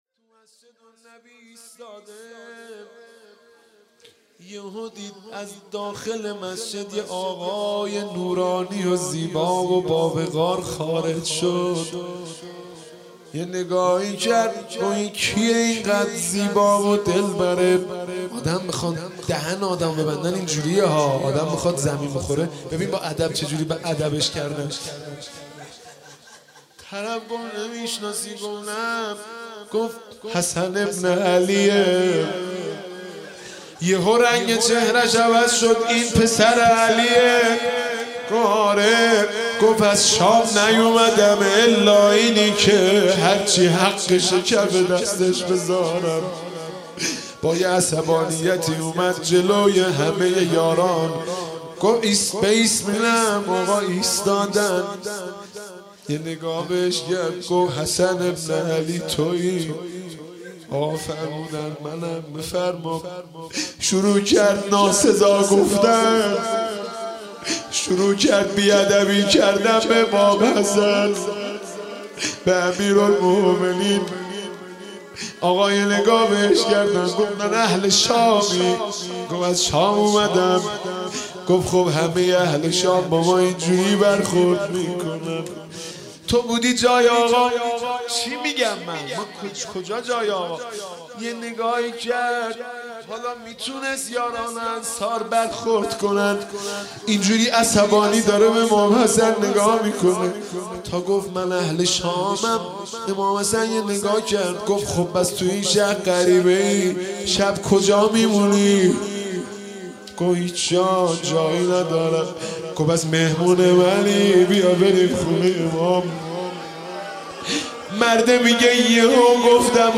شب 15 رمضان 97 - روضه امام حسن علیه السلام